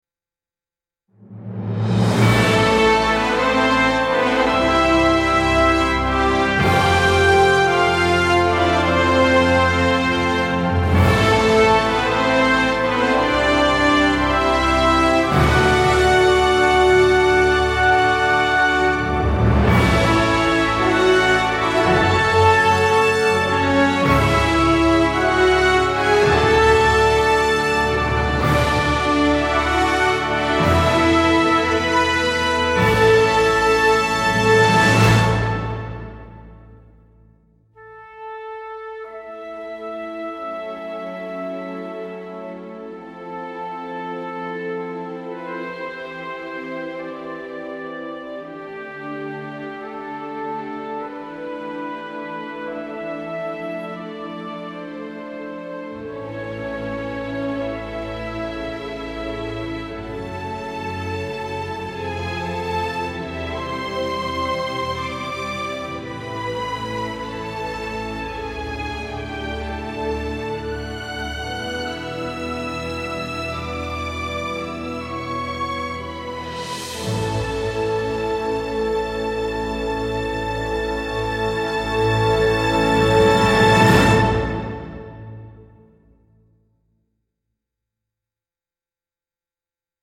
regal fanfare transitioning into a graceful string serenade